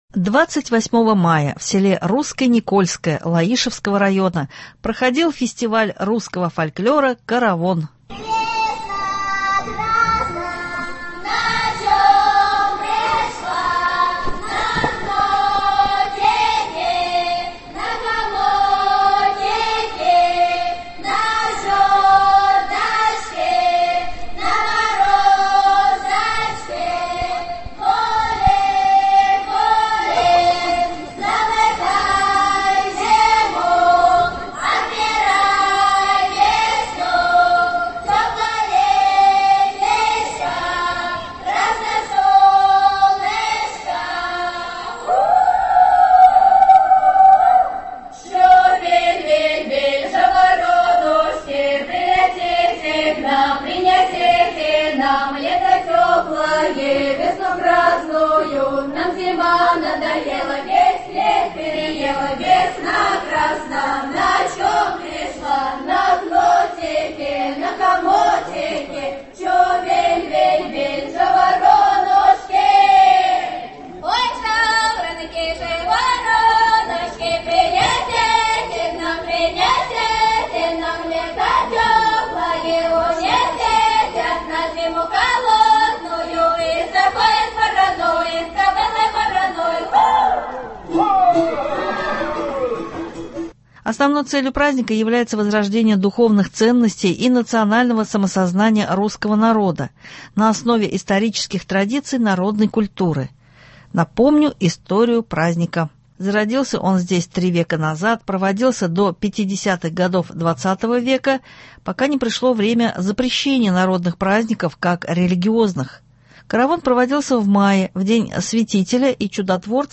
Репортаж с праздника русской культуры «Каравон».
28 мая в селе Русское Никольское Лаишевского района проходил фестиваль русского фольклора «Каравон».